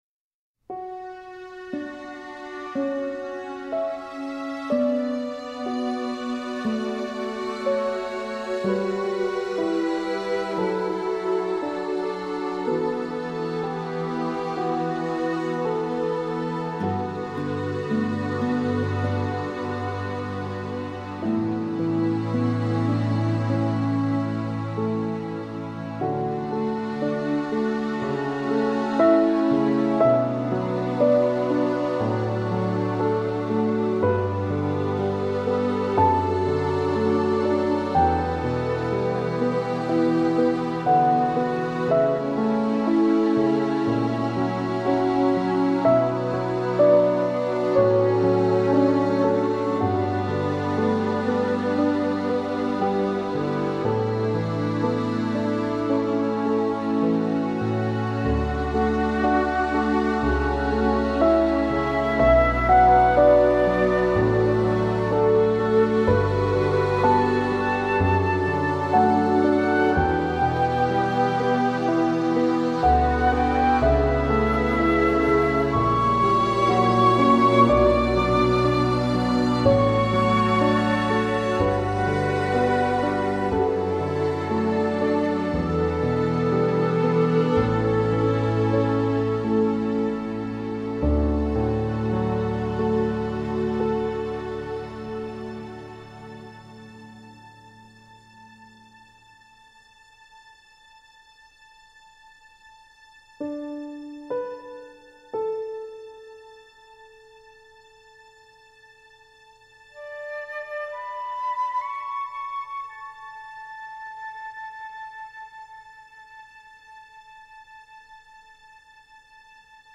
خودگویی مثبت (self talking) از فنون روان شناسی رفتاری - شناختی و روشی برای تقویت ذهن و ارتقای انرژی مثبت است. در این فایل مطالب خودگویی با موسیقی آرام بخش همراه شده و سیر زیبا و اثر بخشی را به وجود می آورد.
آرام بخش: